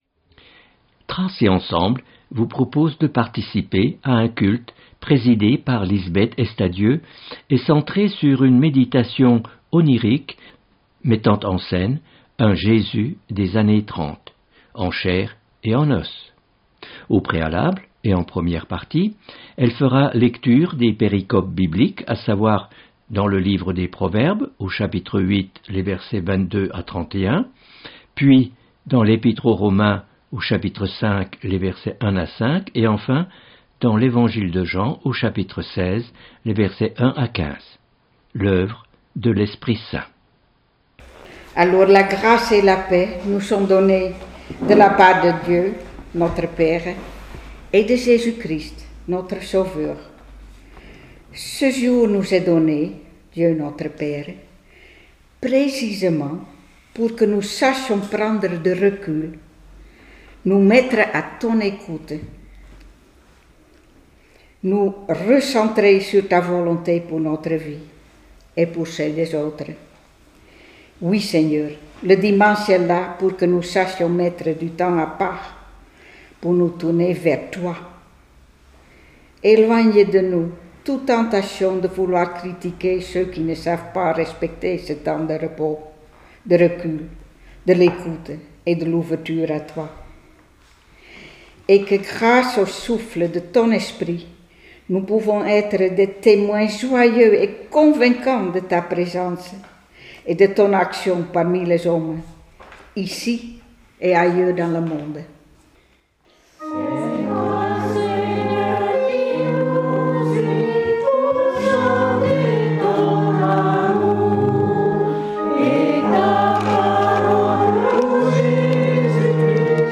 Culte